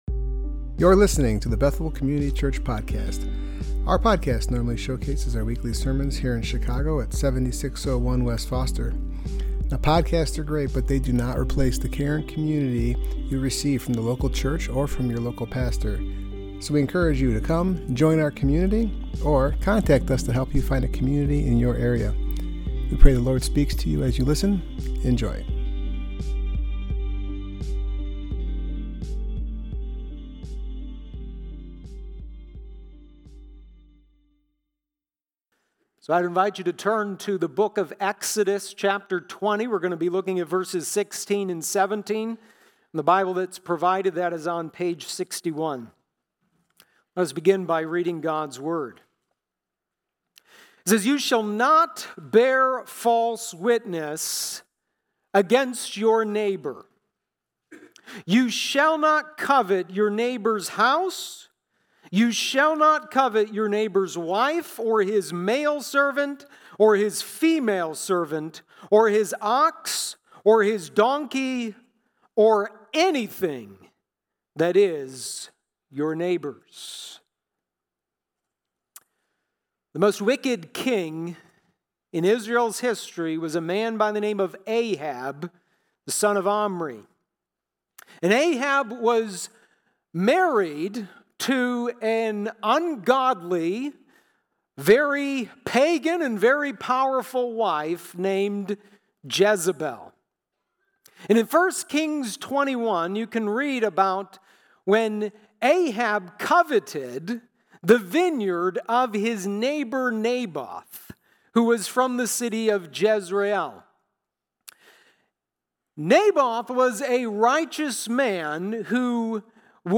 Service Type: Worship Gathering